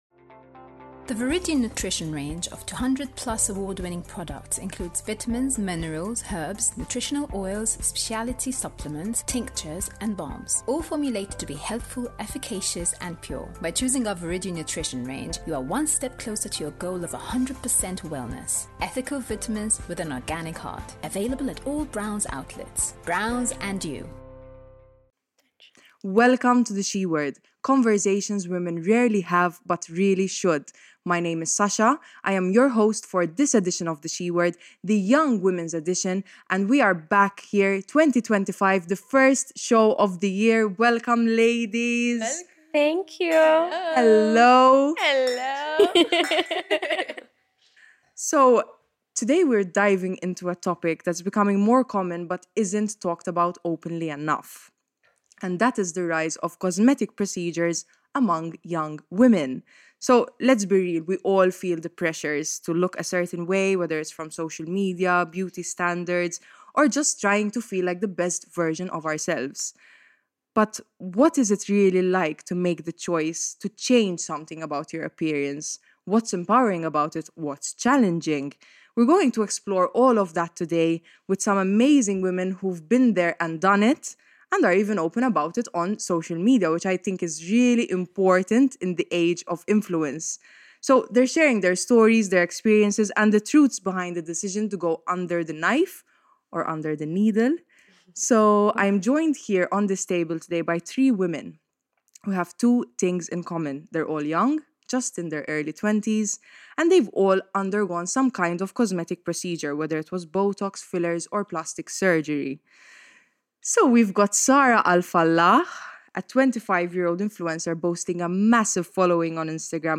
three young women who have not shied away from cosmetic procedures